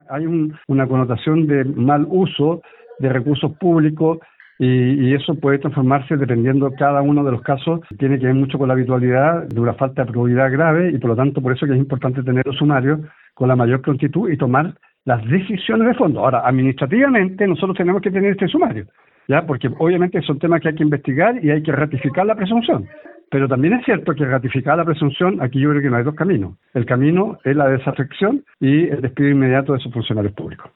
Así lo anunció el gobernador, Alejandro Santana, afrimando que de comprobarse irregularidades, los involucrados serán desvinculados de la institución.
Además, en conversación con Radio Bío Bío, anunció máxima celeridad en estos procesos, ya que se espera dar una señal de que este tipo de acciones no se van a permitir en el ámbito público.